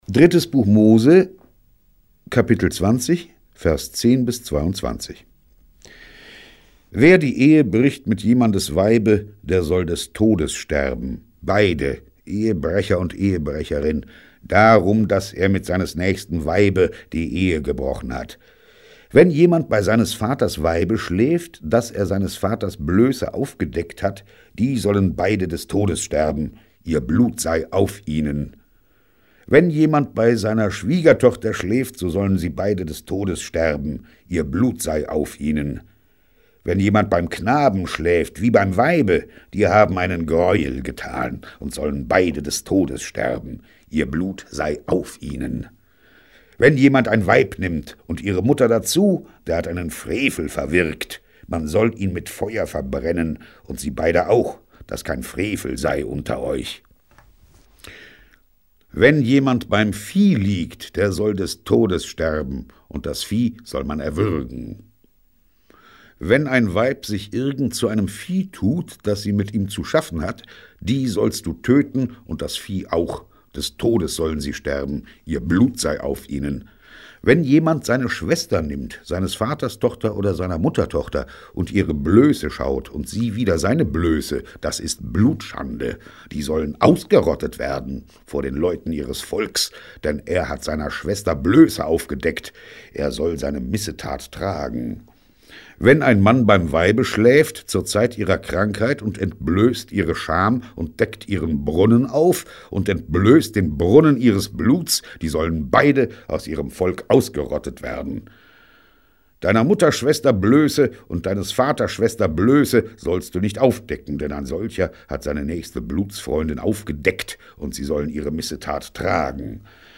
Harry Rowohlt (Sprecher)